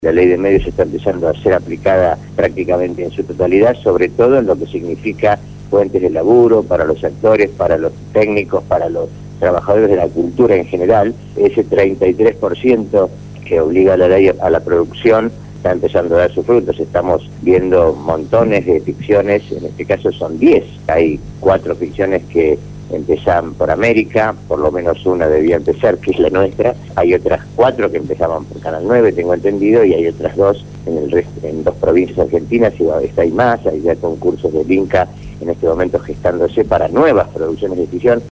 La  entrevista fue realizada en el programa Punto de Partida de Radio Gráfica FM 89.3